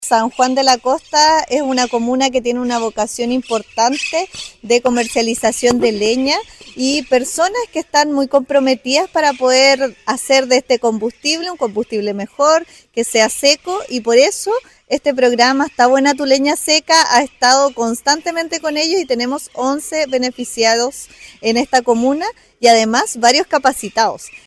Al respecto, la seremi Liliana Alarcón, explicó que la entrega de estos medidores de humedad es fundamental para asegurar que la leña cumpla con un porcentaje de humedad no superior al 25%.